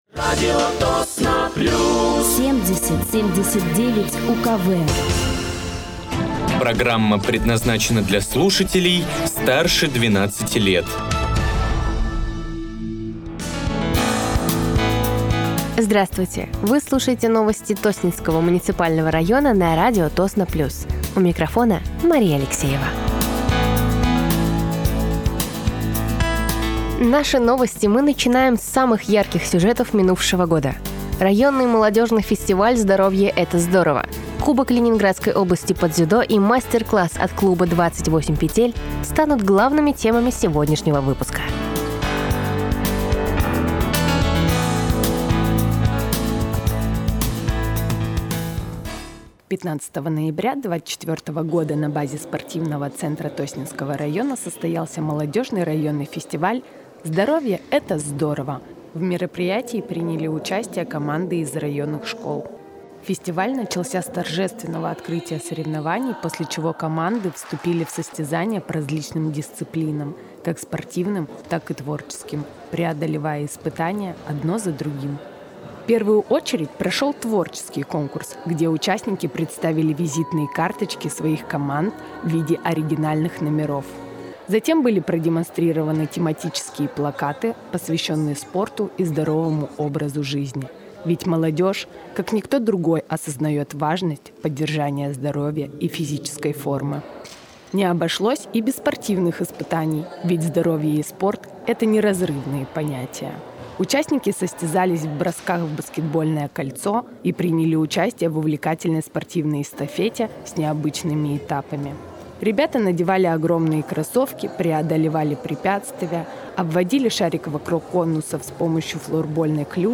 Вы слушаете новости Тосненского муниципального района на радиоканале «Радио Тосно плюс».